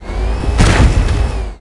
wood.ogg